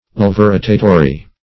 Laevorotatory \L[ae]"vo*ro"ta*to*ry\, a.